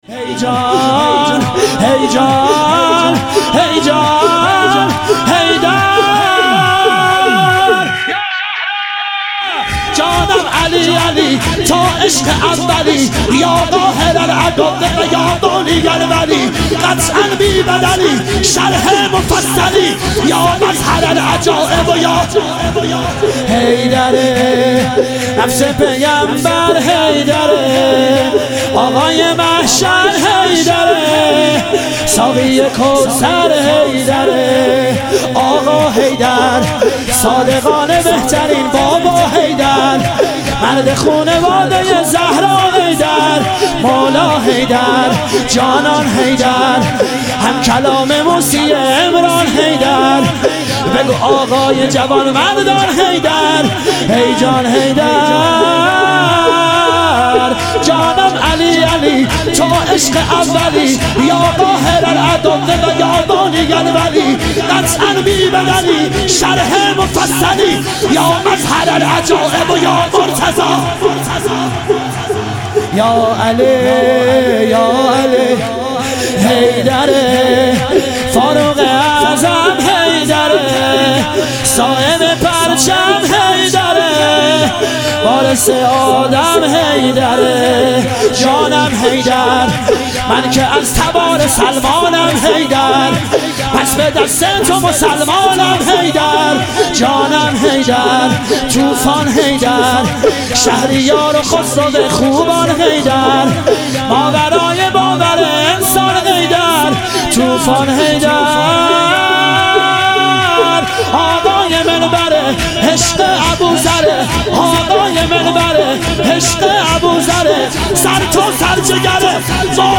عید سعید غدیر خم - شور